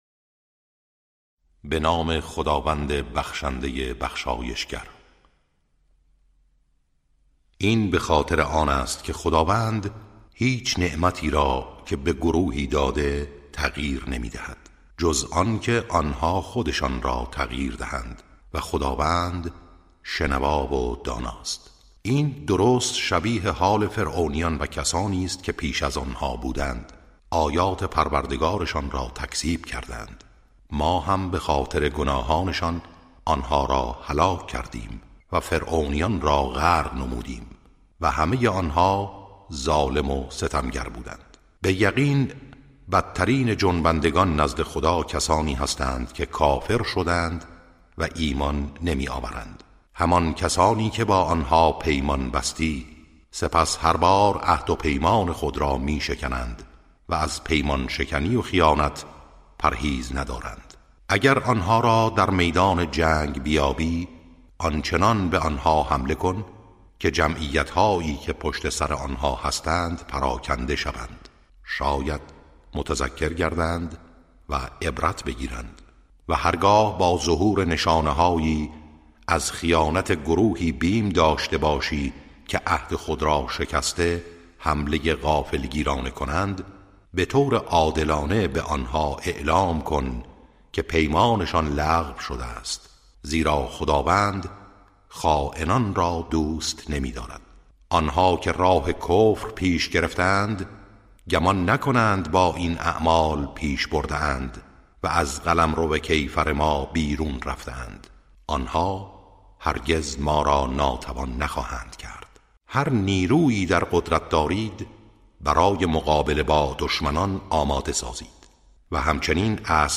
ترجمه سوره(انفال)